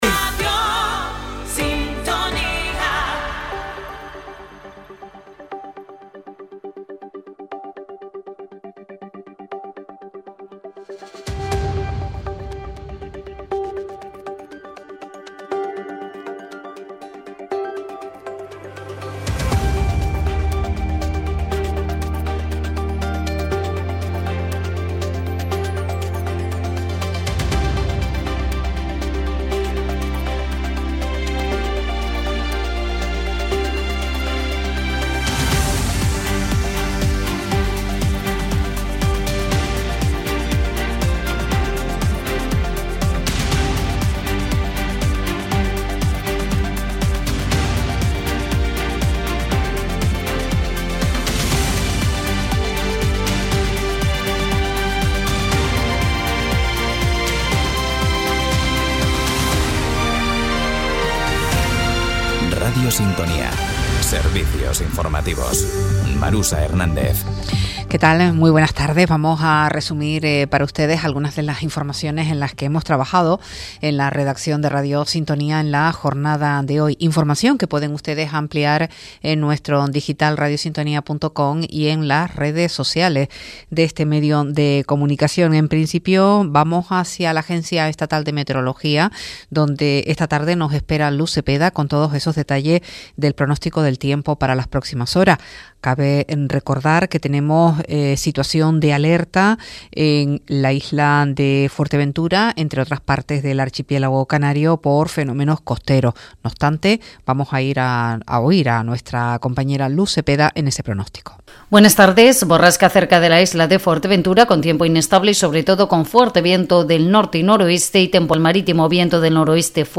En él te contamos, en directo, las noticias más importantes de la jornada, a partir de las 13:15h.